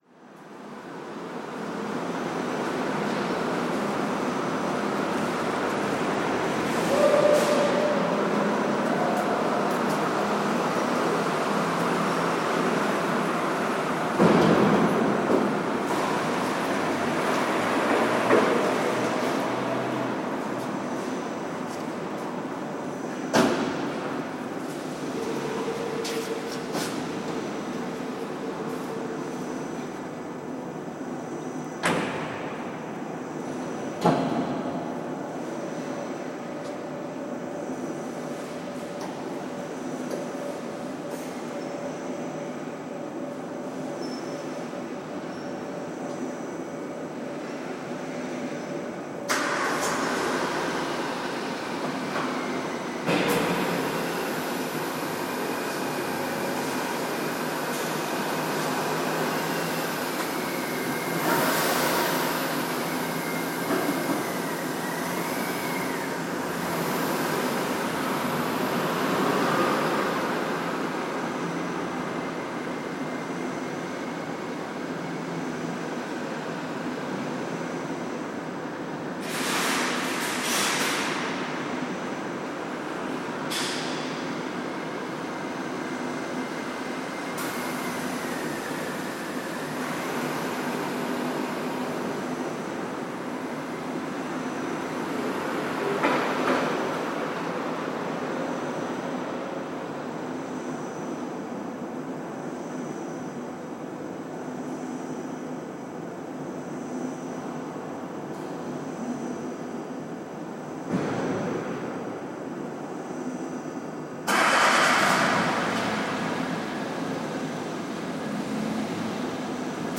Dutch underground garage, Amsterdam
The sounds of a parkeergarage in Amsterdam